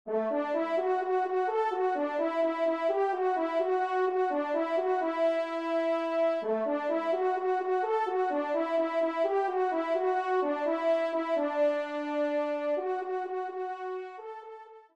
Trompe Solo (TS)